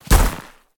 Sfx_creature_snowstalker_walk_03.ogg